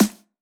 drum-slidertick.wav